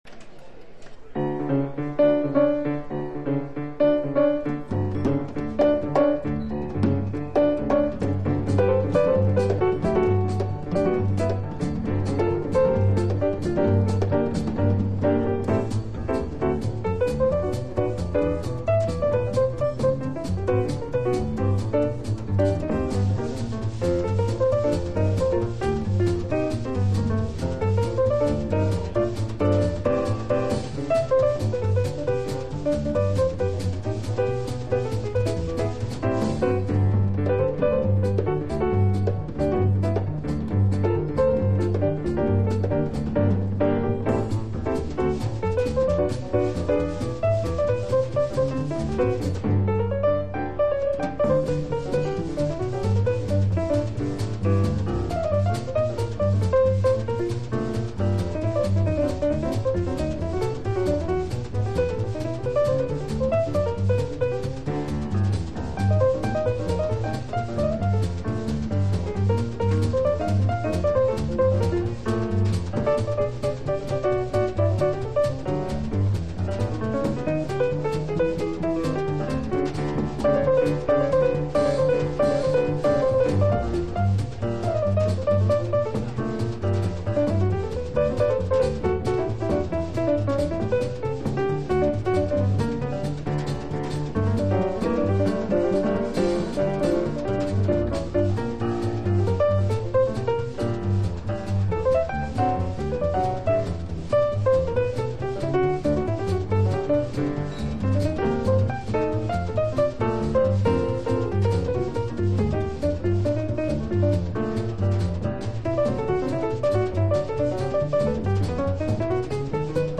（プレス・小傷によりチリ、プチ音ある…